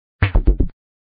Вы можете слушать онлайн и скачать бесплатно в mp3 рингтоны входящих звонков, мелодии смс-уведомлений, системные звуки и другие аудиофайлы.